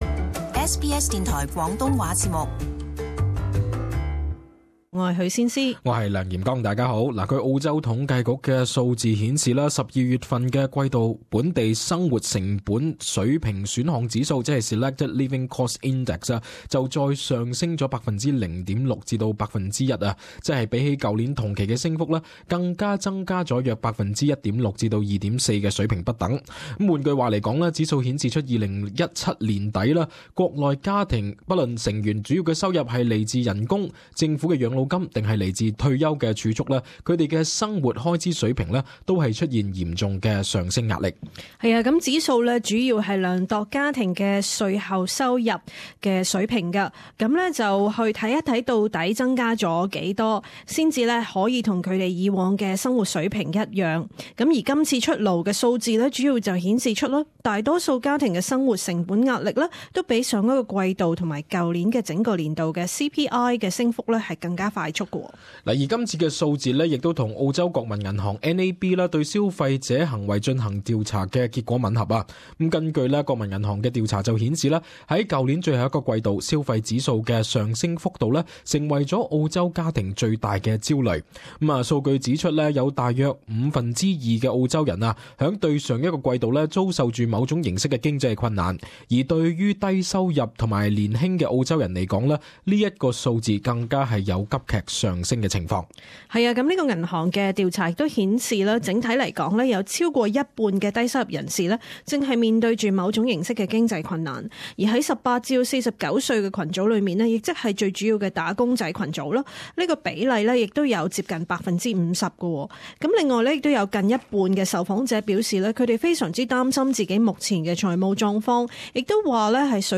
【時事報導】統計局數據證實澳洲生活開支壓力巨大